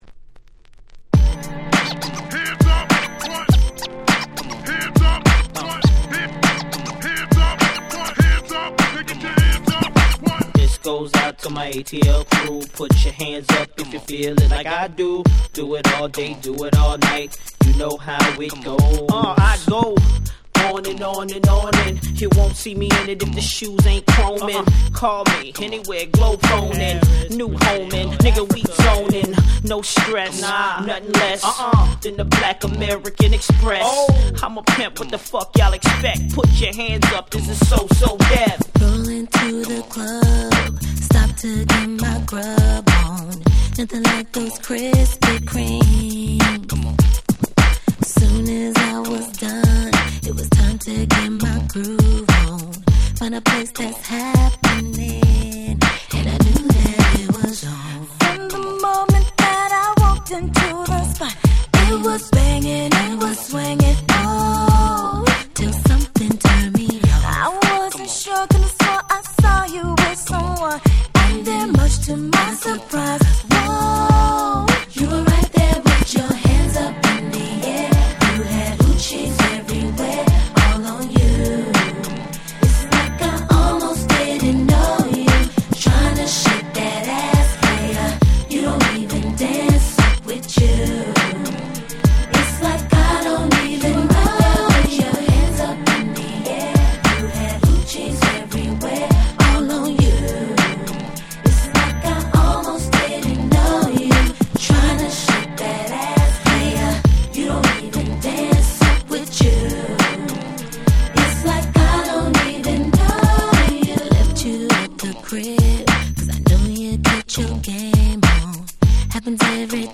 02' Smash Hit R&B !!